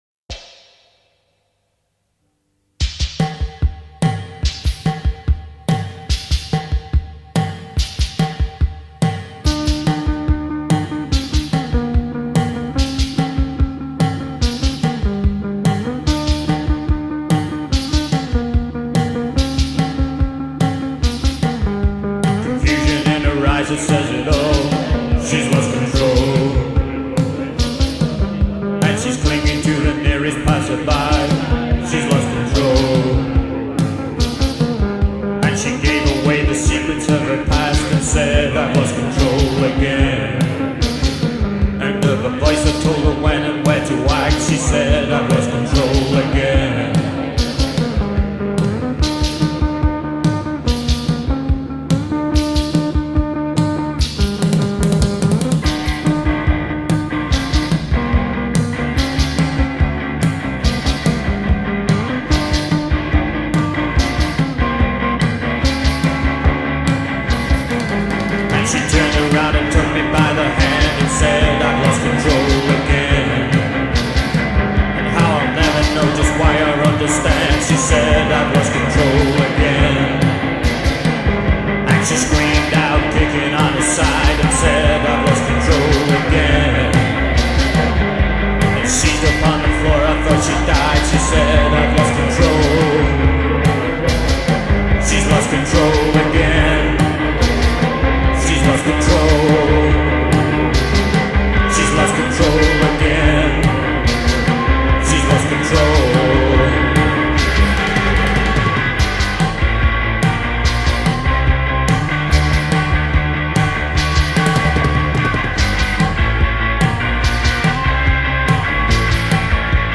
Punk Rock Post rock